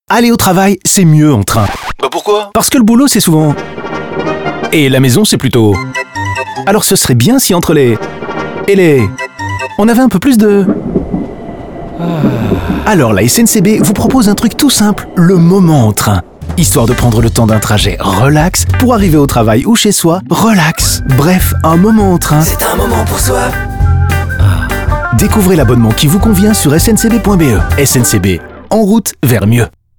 Radio
En radio, le nouveau format au ton humoristique déjà présent depuis quelques campagnes sied à merveille à ce message.